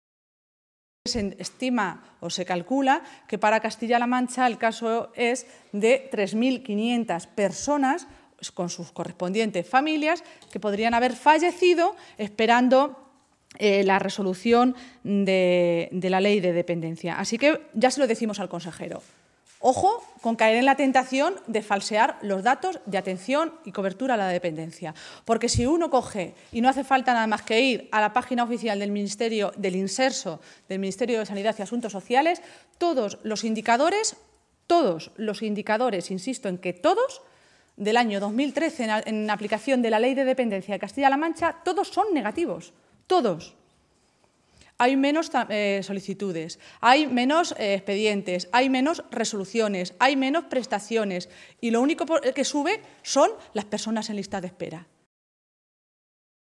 Maestre se pronunciaba de esta manera esta mañana, en Toledo, en una comparecencia ante los medios de comunicación minutos antes de que el consejero de Sanidad y Asuntos Sociales ofreciera una rueda de prensa para hacer balance de la aplicación de la Ley de la Dependencia en la región a lo largo del pasado año 2013.